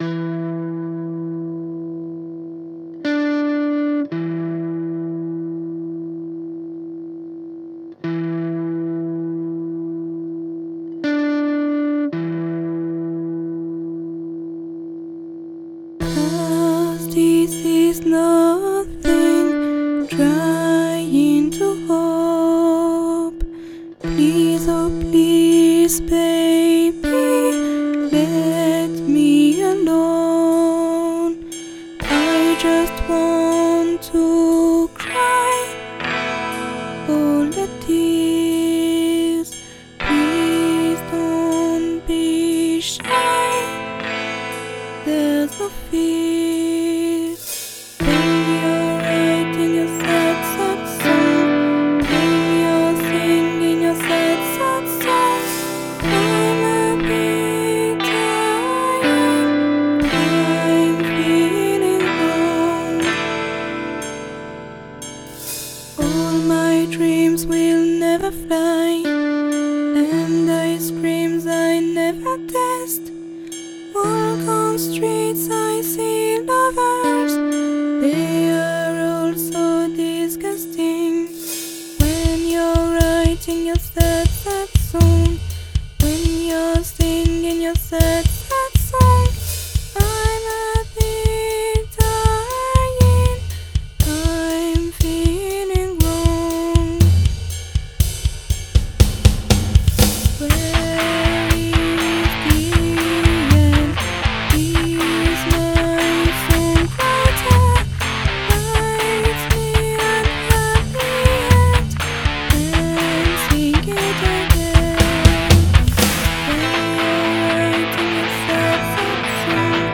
2008, Soupe pop pour radio